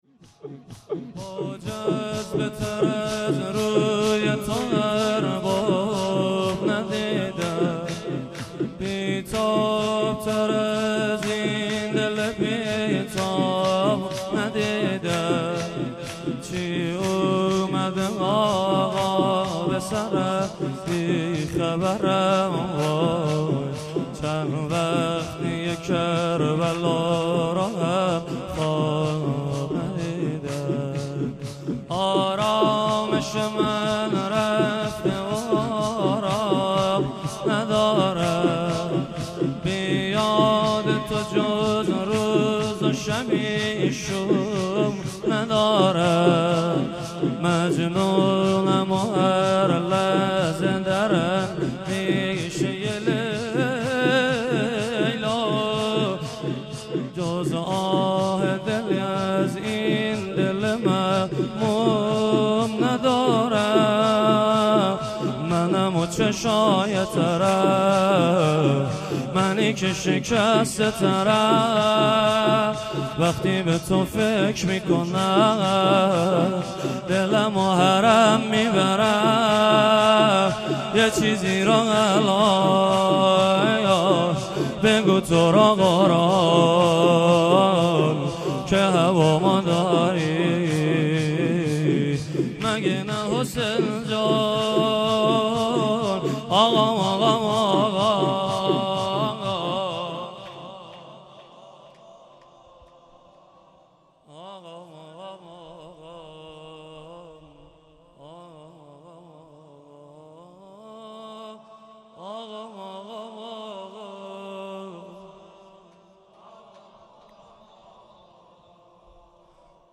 خیمه گاه - بیرق معظم محبین حضرت صاحب الزمان(عج) - شور | بی تاب تر از این دلم